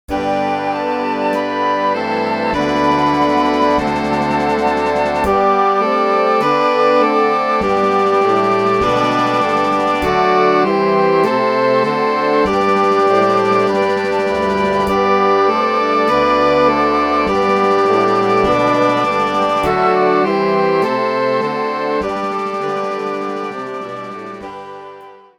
Kościelna